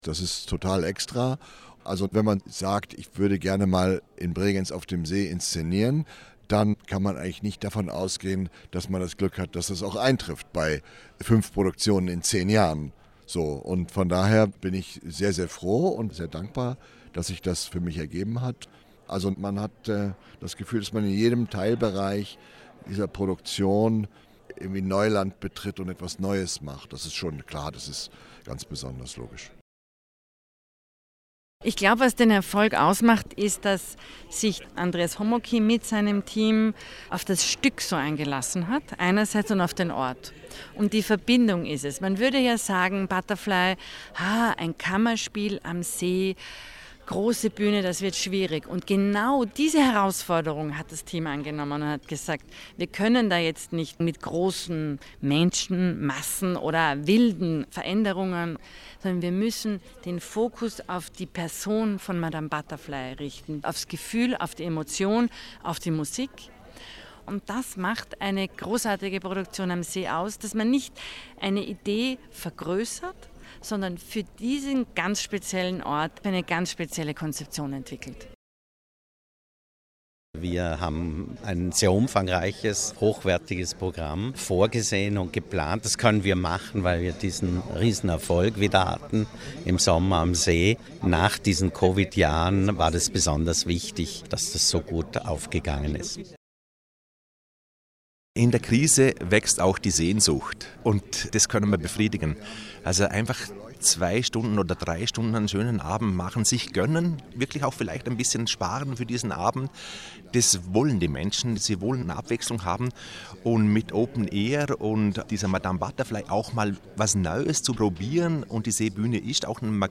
O-Ton Programmpräsentation 2023 - Feature